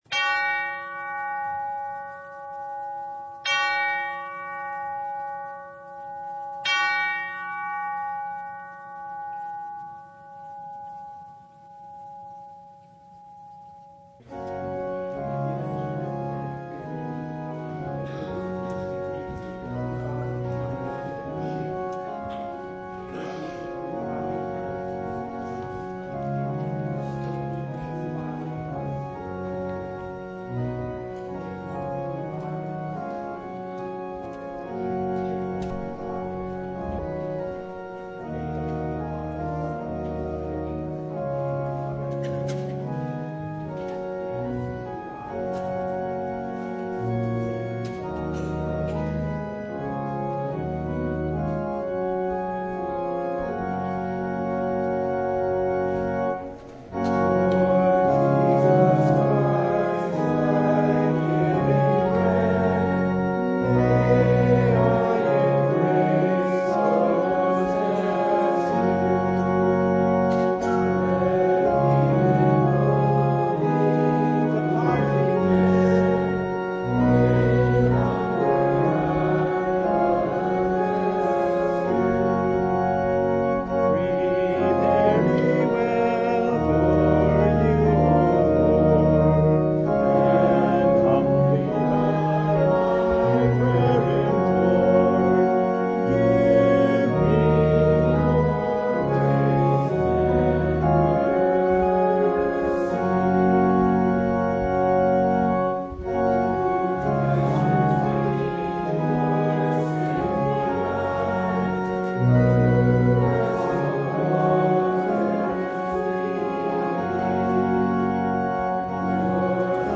John 6:35–51 Service Type: Sunday Jesus is bread from heaven?